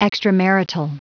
Prononciation du mot extramarital en anglais (fichier audio)
Prononciation du mot : extramarital